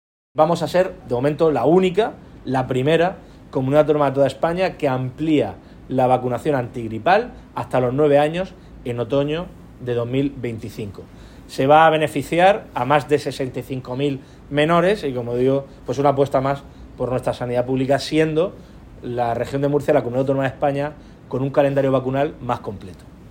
Declaraciones del presidente del Gobierno de la Región de Murcia, Fernando López Miras, sobre el